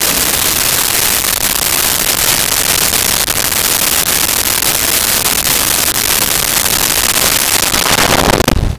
Fire Blast 01
Fire Blast 01.wav